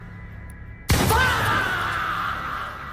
Sound Effects
Shotgun Fah